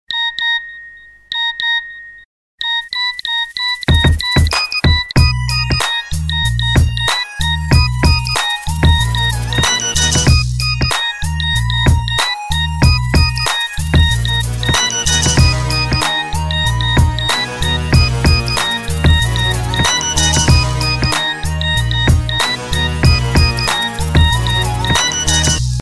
• Качество: 128, Stereo
веселые
Рингтон на смс Нокиа в обработке